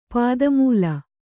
♪ pāda mūla